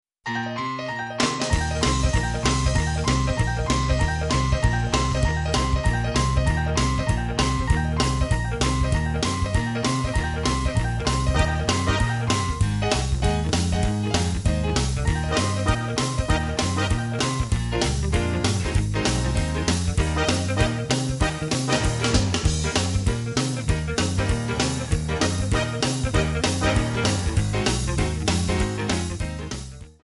Eb
Backing track Karaoke
Pop, Oldies, Jazz/Big Band, 1950s